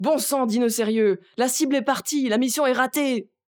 VO_ALL_EVENT_Temps ecoule_01.ogg